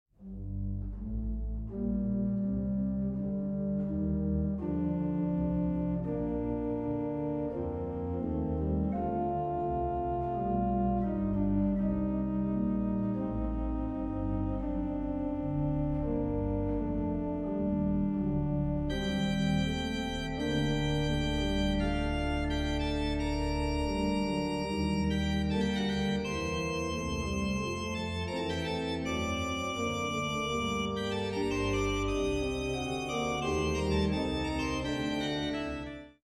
1980 Ahrend organ in Monash University, Melbourne
Organ